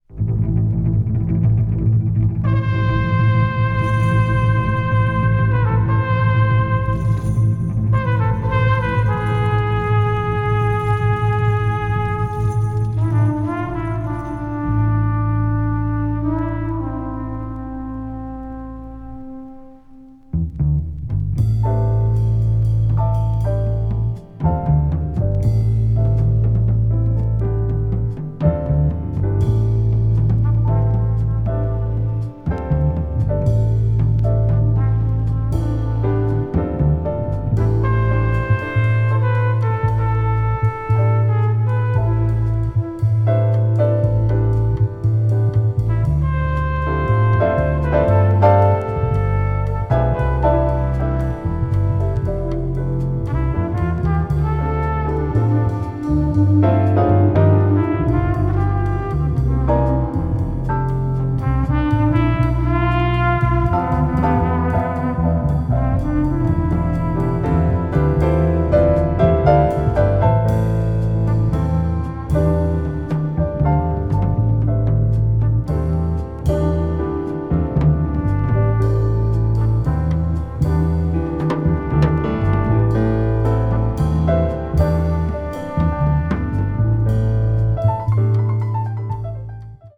contemporary jazz   post bop   spritual jazz